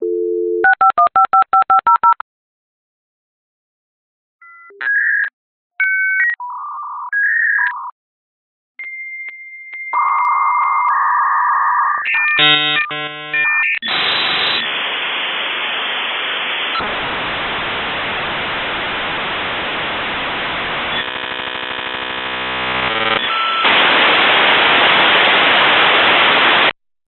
PCをダイアルアップでネットに接続するのですがこの時の接続音がこんな感じです。